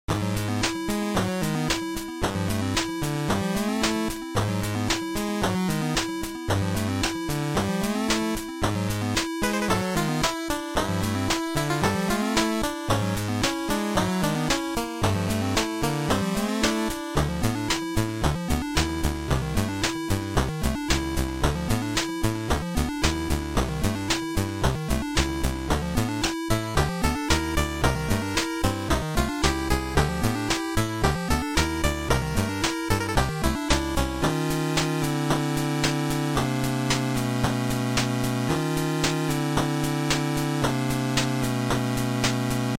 Video Game soundtracks